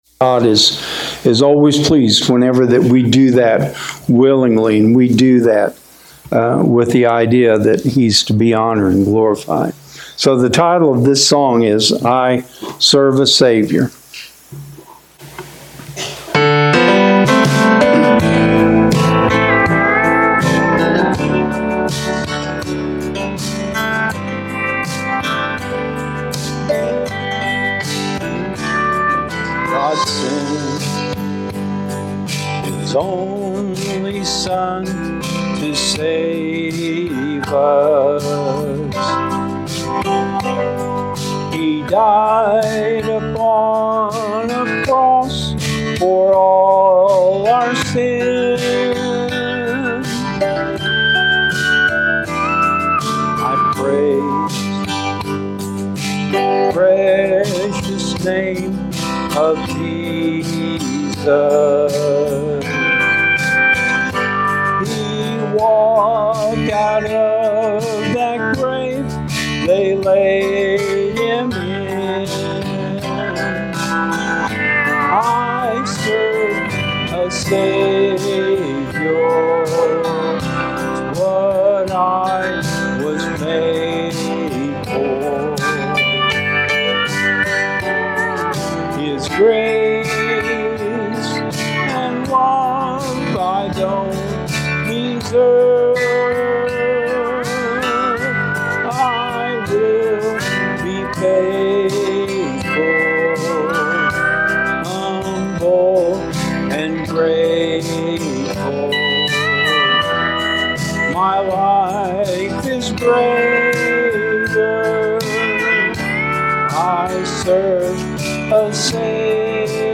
Links to Elkton Baptist Church sermons recorded in 2026 are listed below: